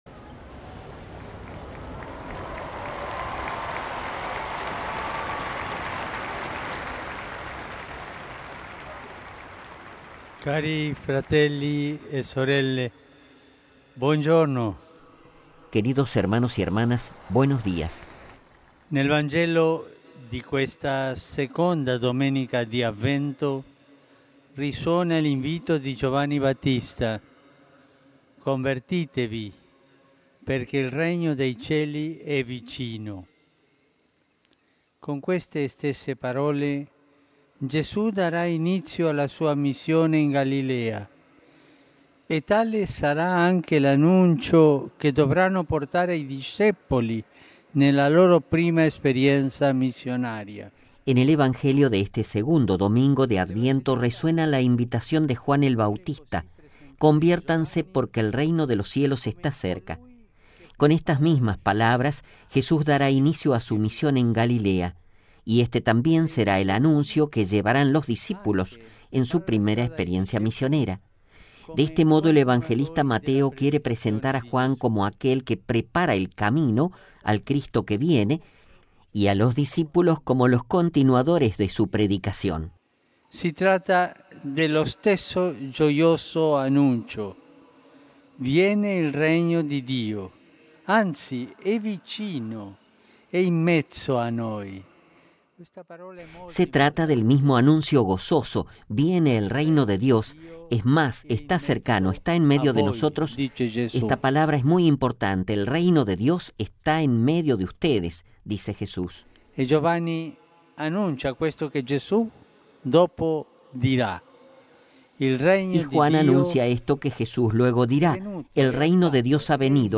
Texto y Audio completo de la reflexión del Papa Francisco, previa a la oración del Ángelus